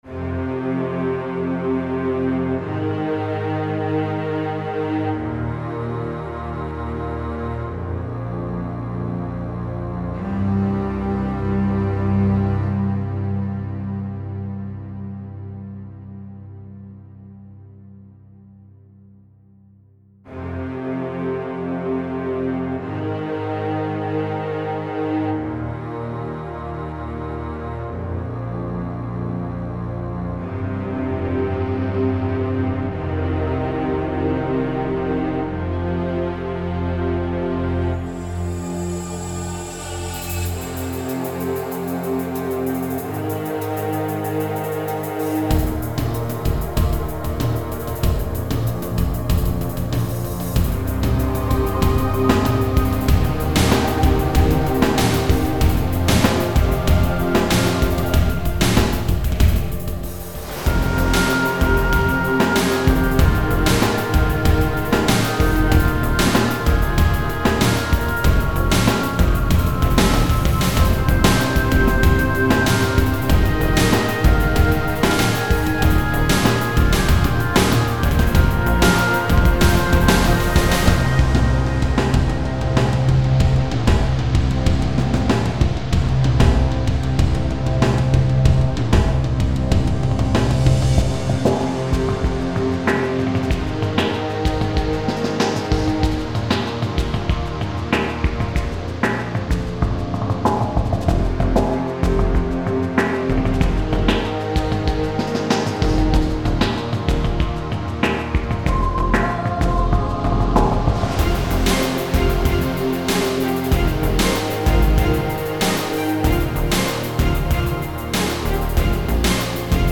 These are links to some sample pieces that I have composed using soundfonts and samples.